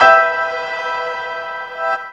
PIANOREVRB-L.wav